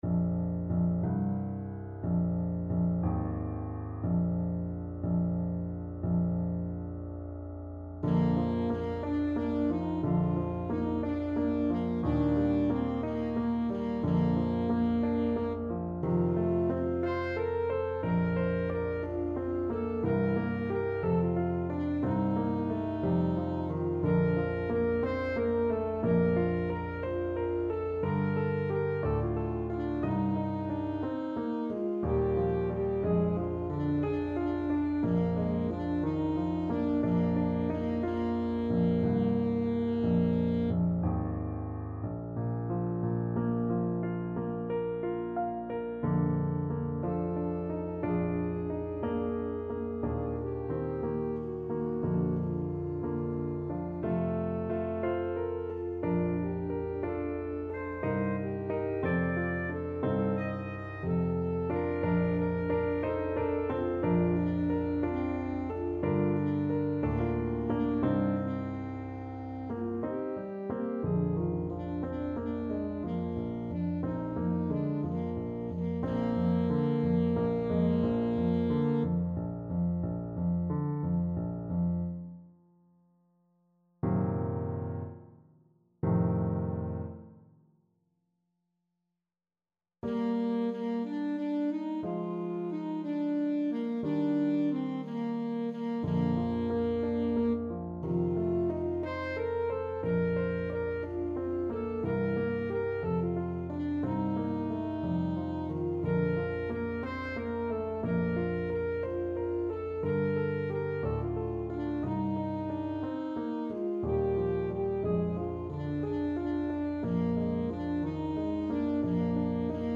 Alto Saxophone
6/8 (View more 6/8 Music)
Andante .=c.60